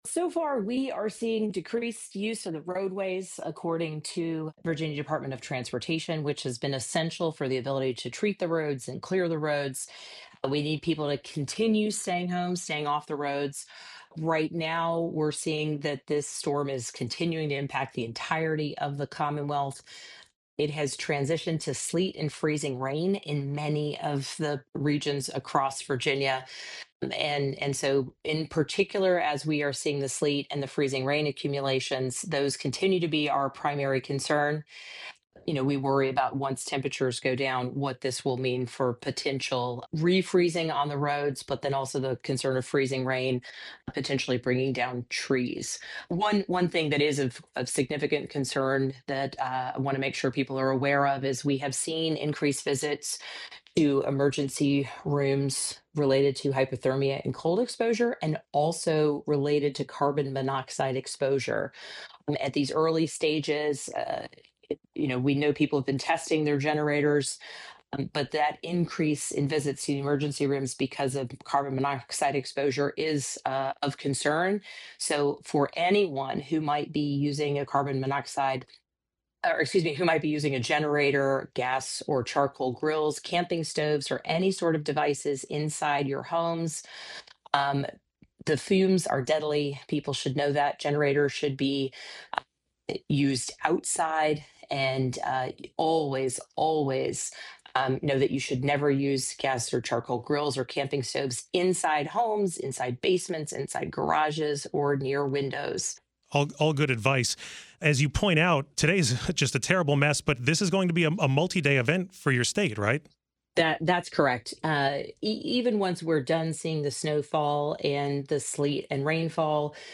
Virginia Gov. Abigail Spanberger joins WTOP to discuss the snowstorm response and the increase in emergency room visits the state is recording.